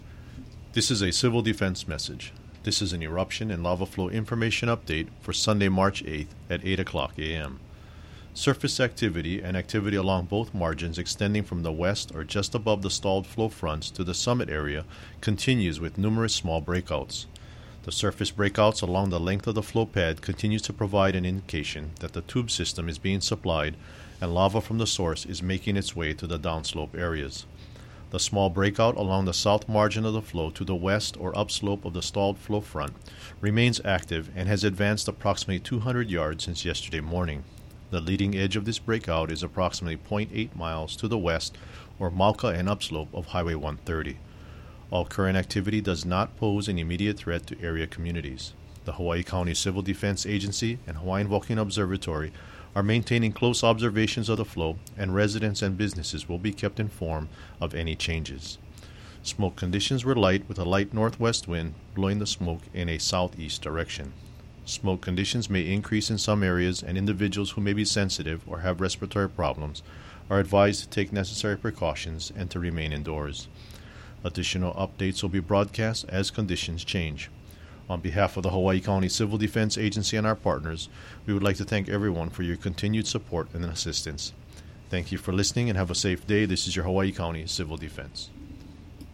CIVIL DEFENSE MESSAGE